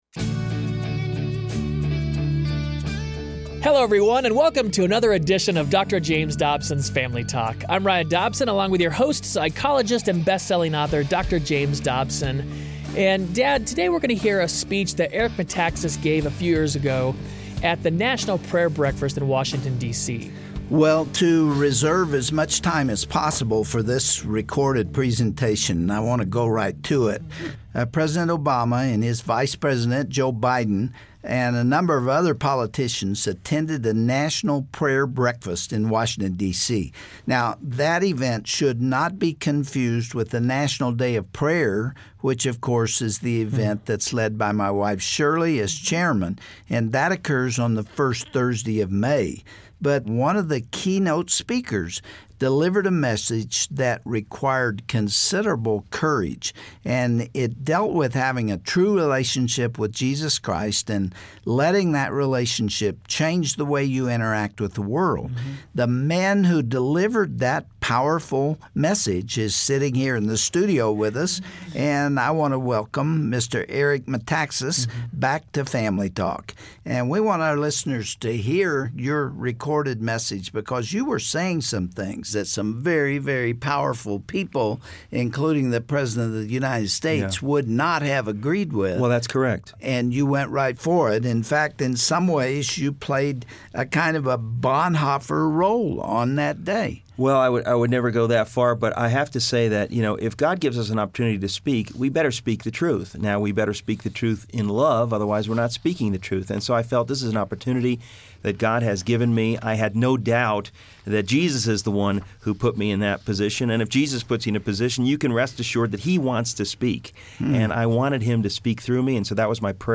Could you stand up for truth even in the presence of someone as powerful as the President of the United States? On the next edition of Family Talk, we will revisit a speech Eric Metaxas gave at the National Prayer Breakfast a few years ago.